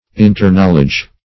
Search Result for " interknowledge" : The Collaborative International Dictionary of English v.0.48: Interknowledge \In`ter*knowl"edge\, n. Mutual knowledge or acquaintance.